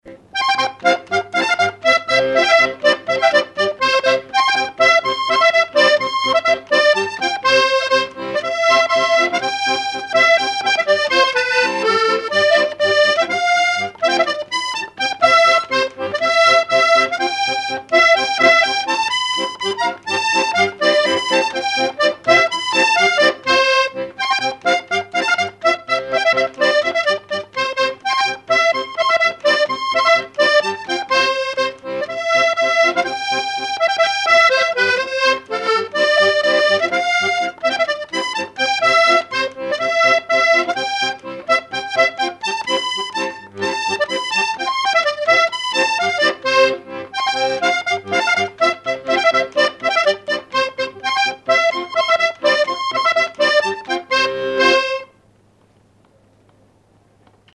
Enregistrement original de l'édition sur disque vinyle
musicien sarthois, musique pour les assauts de danse et le bal.
accordéon(s), accordéoniste ; musique traditionnelle
danse : quadrille